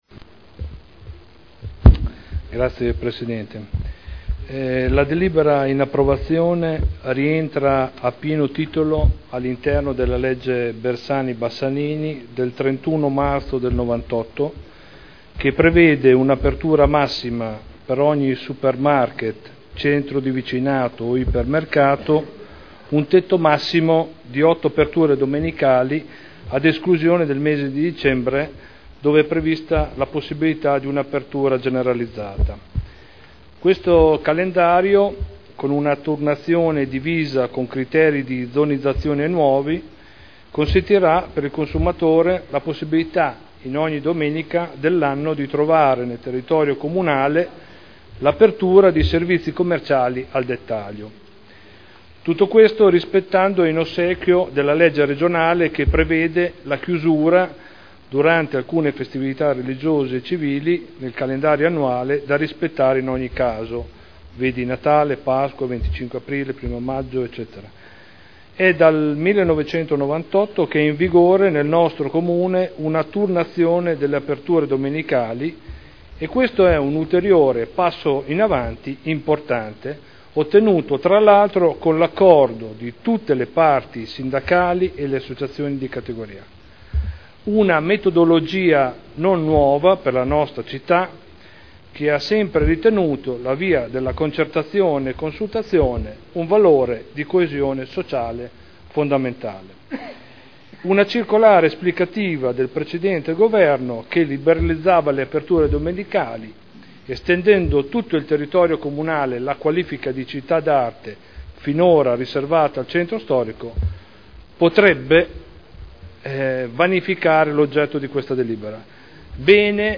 Dibattito.